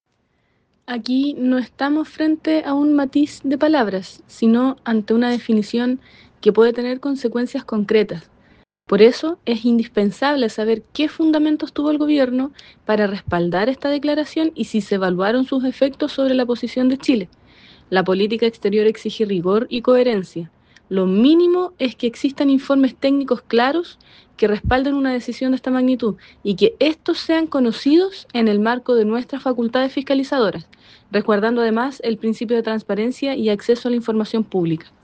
La diputada Coca Ñanco, integrante de la Comisión de Relaciones Exteriores, señaló que la firma del presidente Kast en el documento podría “tener consecuencias concretas”.
coca-nanco.mp3